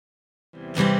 [Teaching Video]